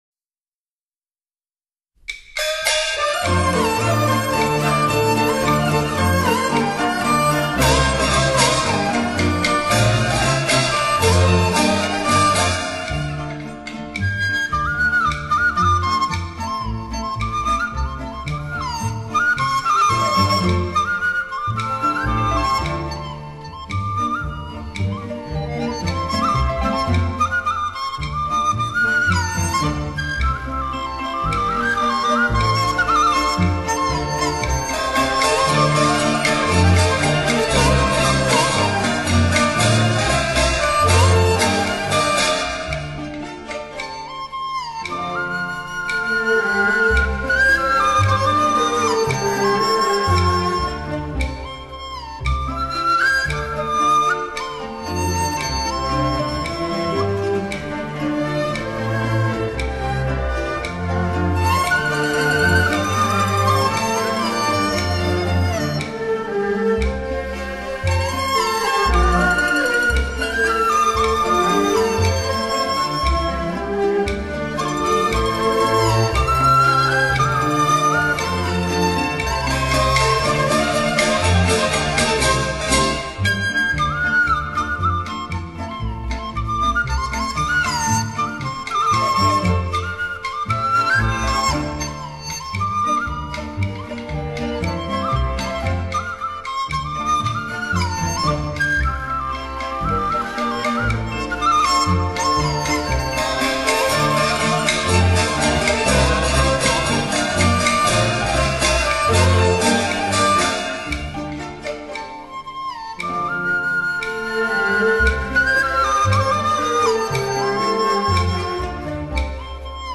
春日景和 戏剧音乐